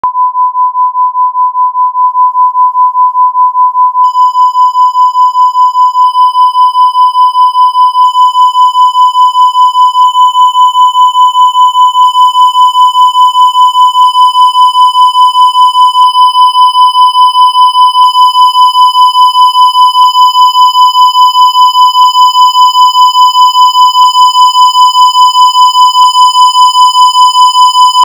Clipping Test
On a simple decoder (as on mobile devices) you will hear clipping until it becomes so strong the waveform just becomes a square wave and you hear additional harmonics.
Output of simple decoder with no clipping protection:
ClippingTest2_0-12dBdecodedNoLimiter.wav